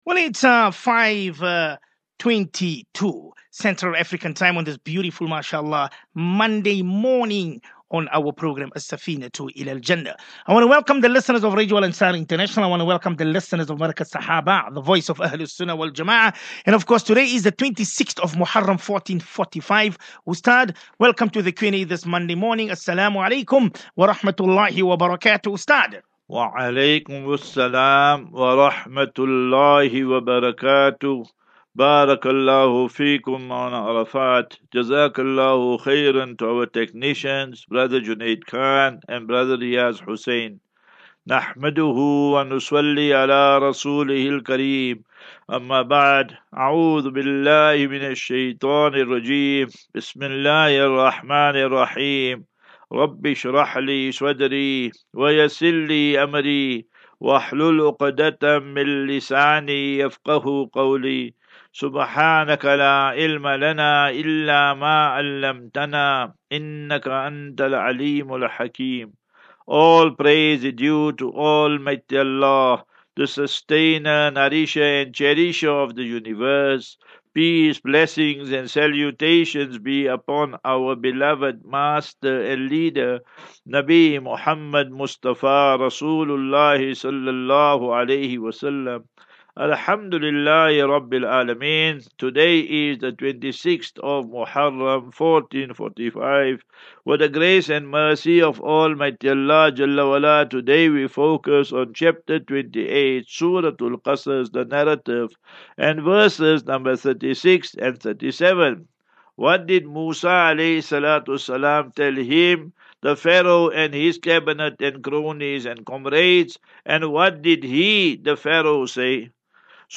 Daily Naseeha.
As Safinatu Ilal Jannah Naseeha and Q and A 14 Aug 14 August 2023.